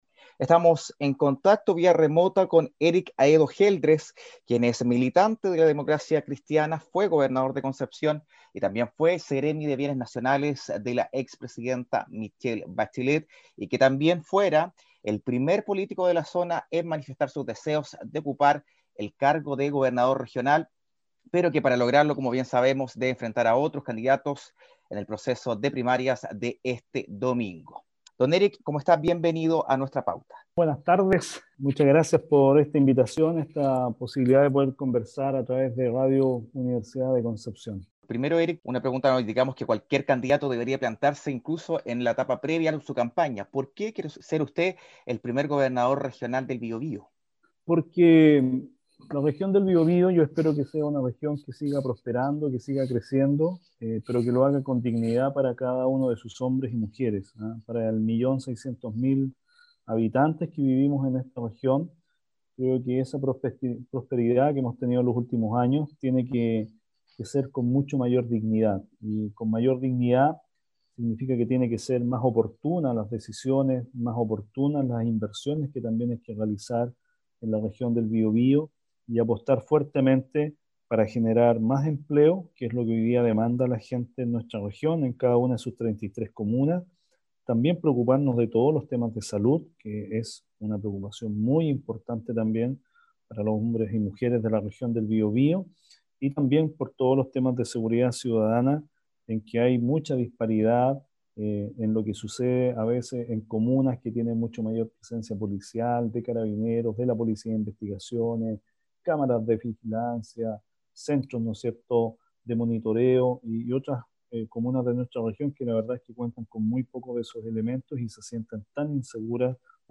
Revisa aquí las entrevistas completas con los cinco postulantes de Unidad Constituyente: